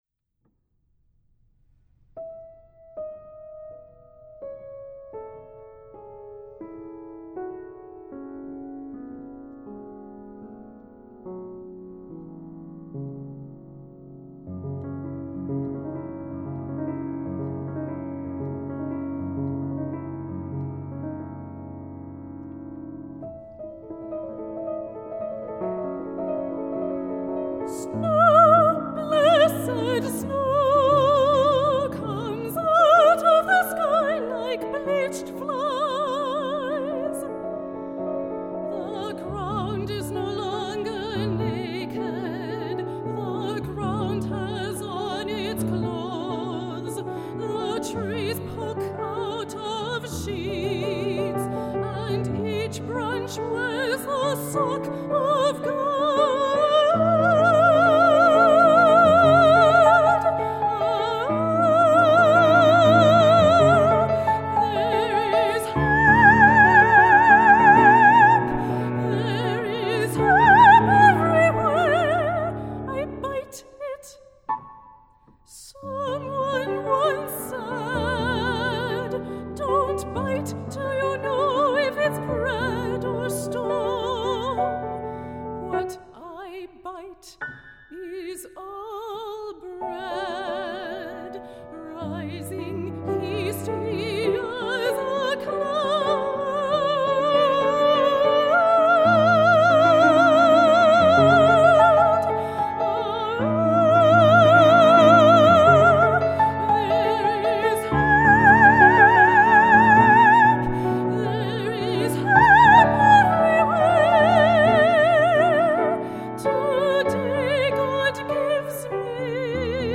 for High Voice and Piano (2013)